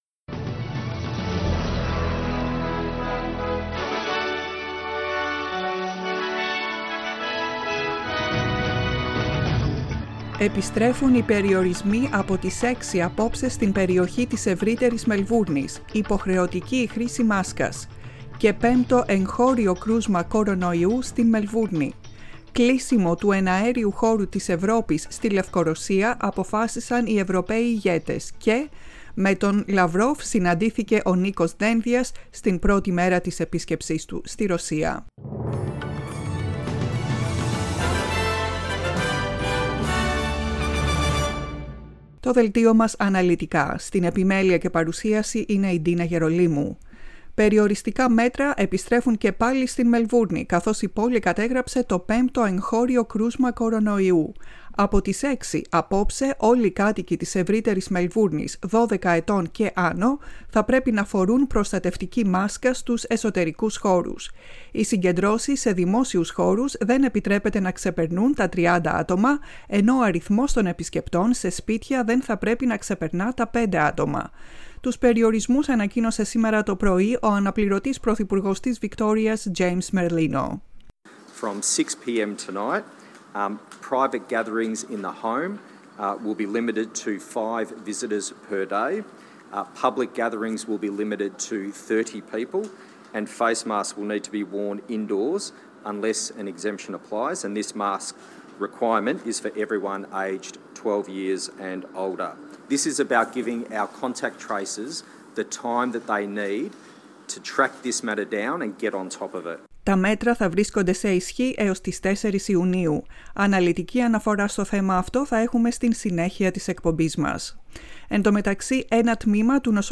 Δελτίο ειδήσεων στα ελληνικά, 25.05.21
Το κεντρικό δελτίο ειδήσεων του Ελληνικού Προγράμματος.